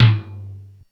LoTom.wav